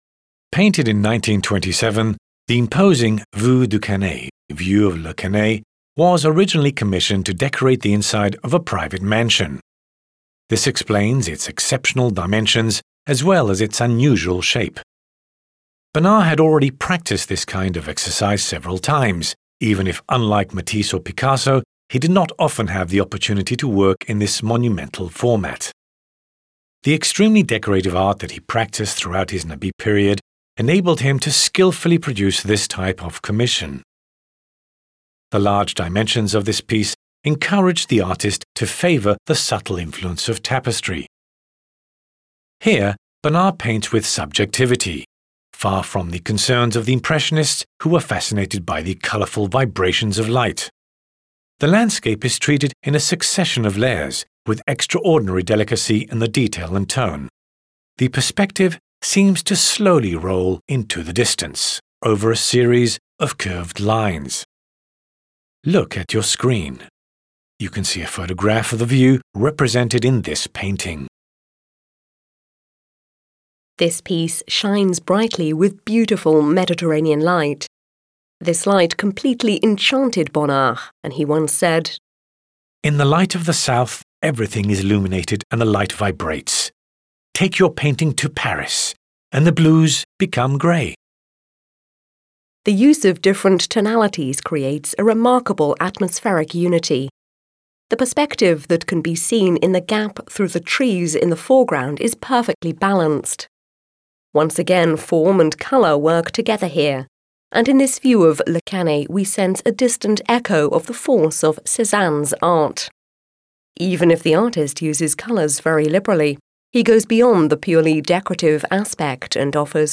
Les audioguides de la Collection